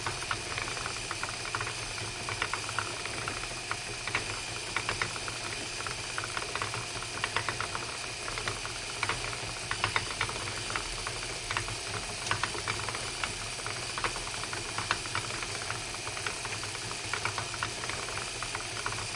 C语言的808轰鸣声
描述：在C的关键时刻有808的混响
标签： 效应808 单触发 滚筒机
声道立体声